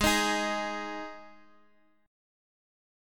Listen to G#5 strummed